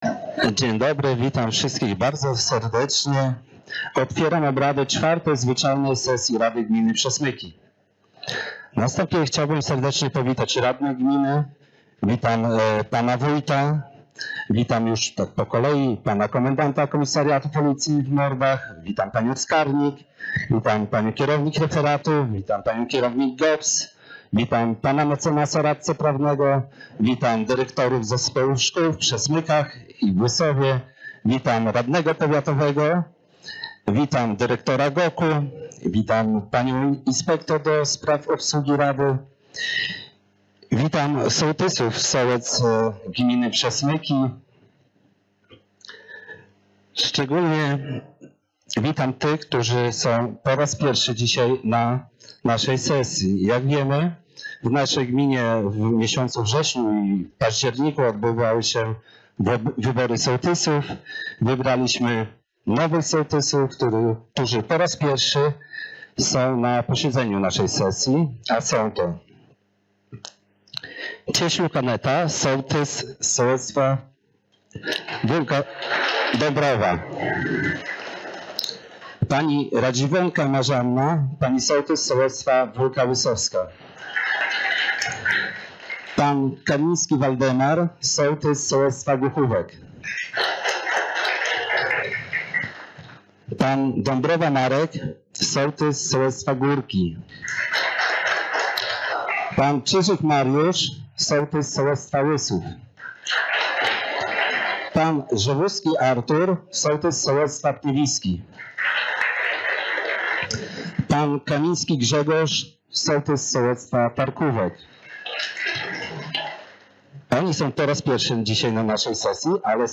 Sesja Rady Gminy Przesmyki – 15.10.2024